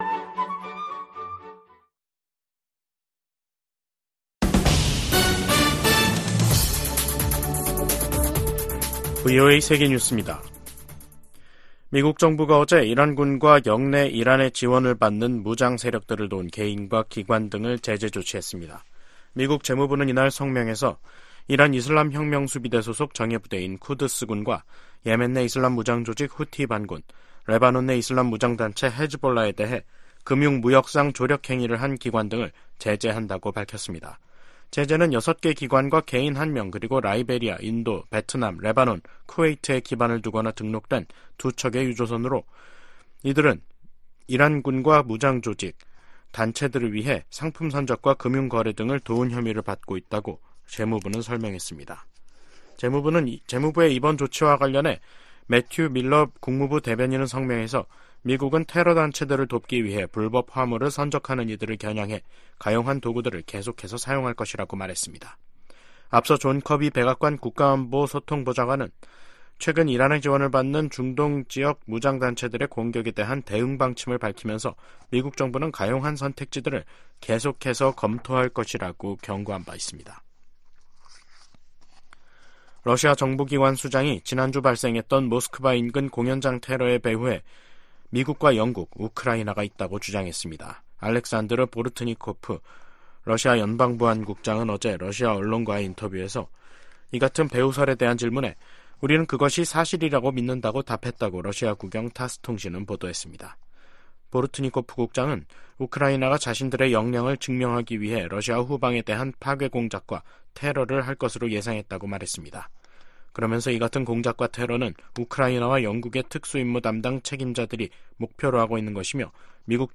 VOA 한국어 간판 뉴스 프로그램 '뉴스 투데이', 2024년 3월 27일 2부 방송입니다. 북한의 김여정 노동당 부부장이 일본과의 정상회담과 관련, 어떤 교섭이나 접촉도 거부할 것이라고 밝혔습니다. 미국 정부가 북한-러시아 군사 협력에 우려를 거듭 표명하고, 북한 무기가 무고한 우크라이나인 살해에 사용되고 있다고 지적했습니다. 기시다 후미오 일본 총리의 다음달 국빈 방미를 계기로 미일 군사 안보 동맹이 업그레이드 될 것으로 전문가들은 전망하고 있습니다.